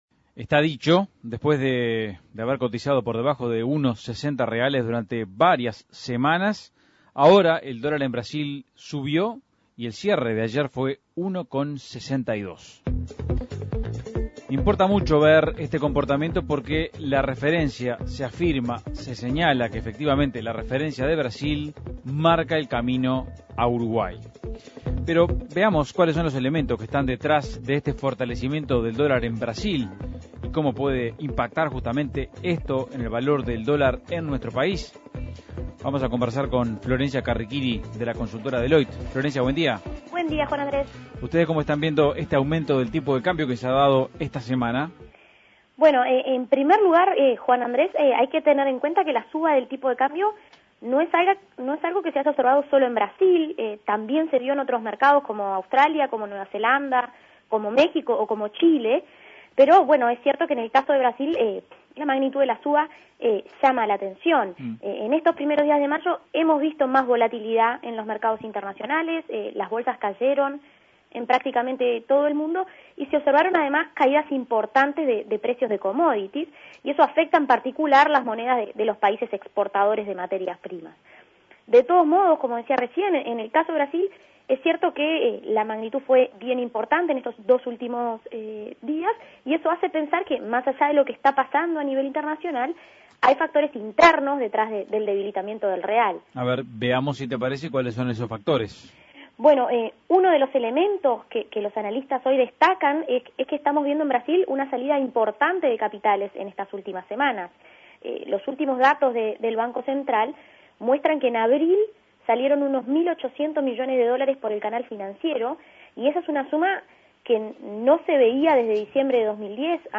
Análisis Económico La cotización del dólar en Brasil subió en los últimos días.